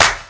Clap [ Grindin' ].wav